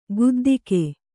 ♪ guddike